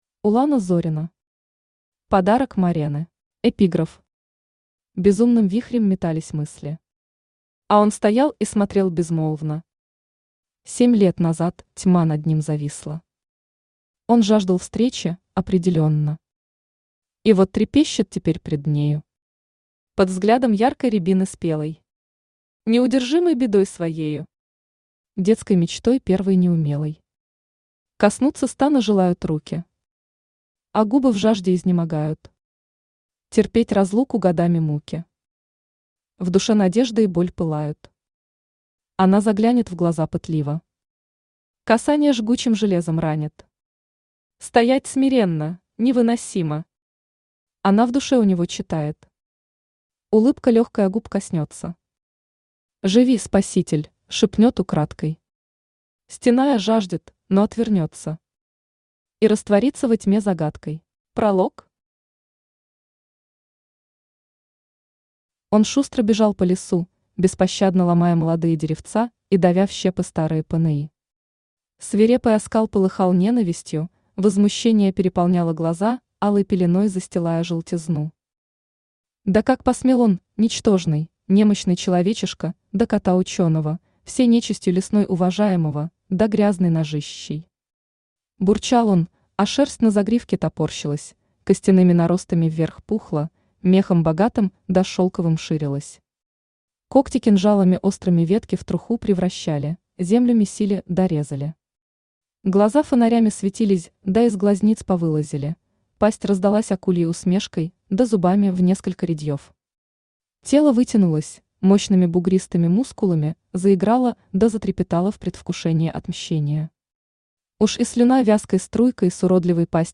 Аудиокнига Подарок Марены | Библиотека аудиокниг
Aудиокнига Подарок Марены Автор Улана Зорина Читает аудиокнигу Авточтец ЛитРес.